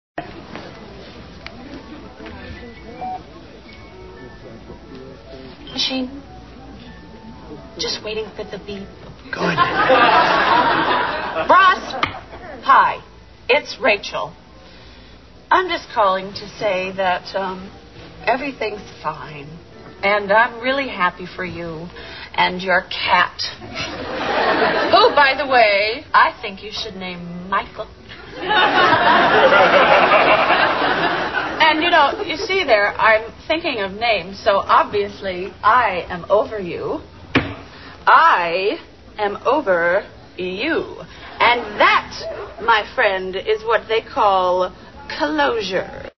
• Category Animals